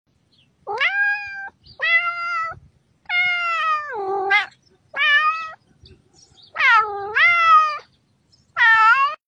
Звуки для котов
Звук, который заставит кота прибежать к вам (соблазнительное мяу)